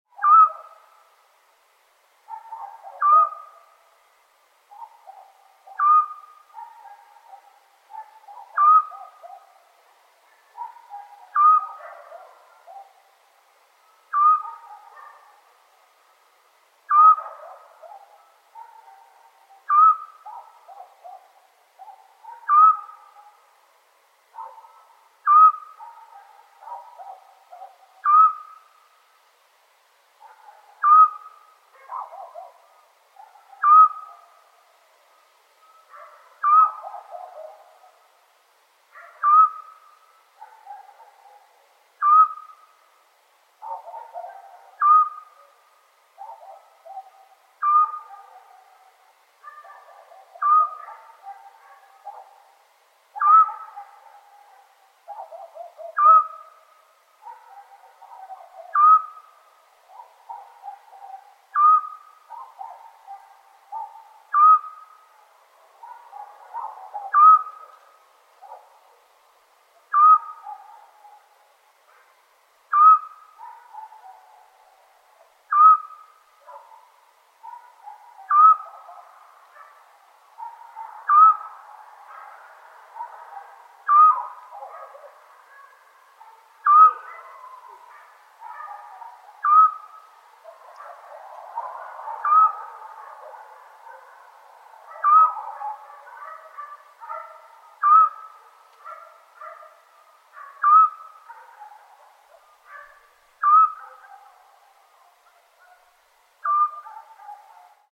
Sound-of-owl-at-night.mp3